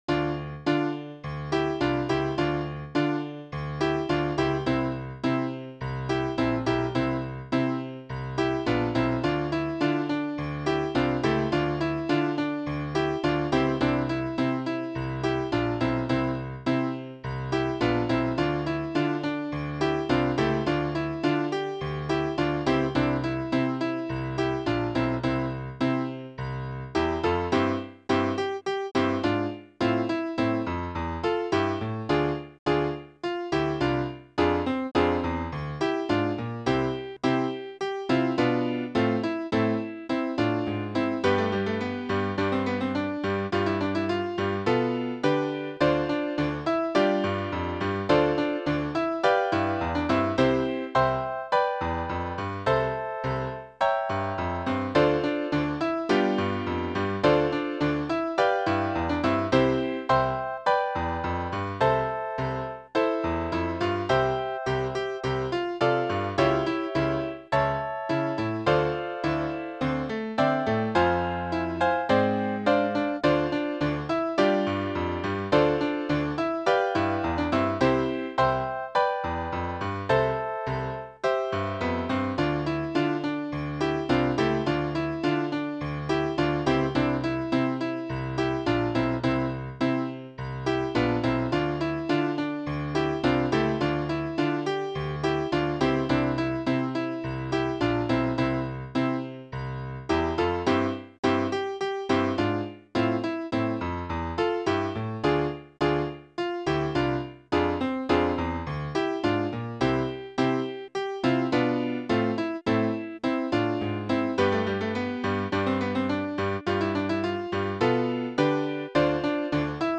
Duet, Piano Solo
Voicing/Instrumentation: Duet , Piano Solo